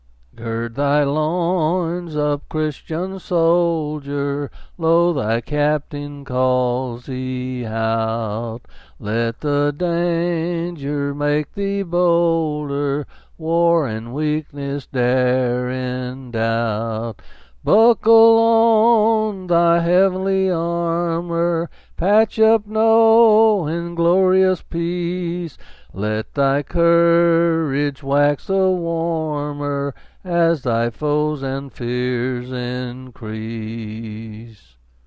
Quill Pin Selected Hymn
8s and 7s D.